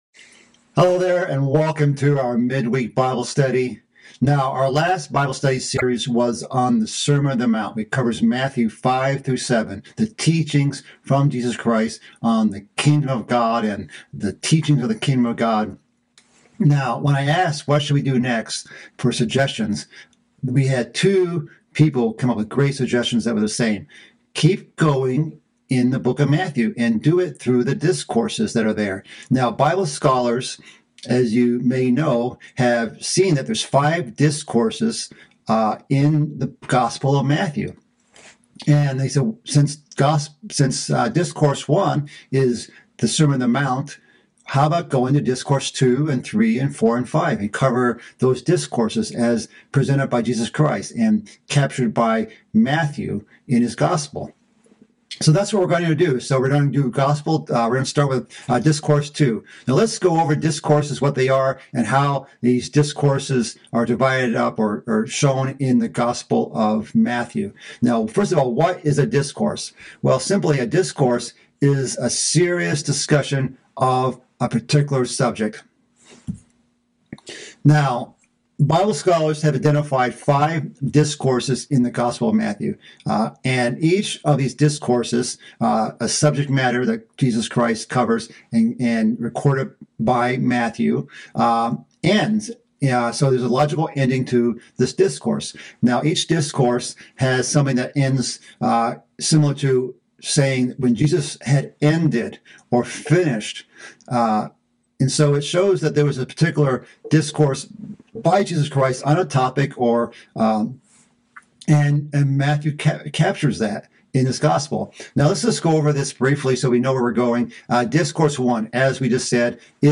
Continuing on from the Sermon on the Mount series, this is the first part of a mid-week Bible study series covering Christ's second discourse in the book of Matthew. This message starts with an overview of the five discourses in Matthew before starting on the first few verses of the second discourse.